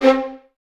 Index of /90_sSampleCDs/Miroslav Vitous - String Ensembles/23 Violins/23 VS Stacc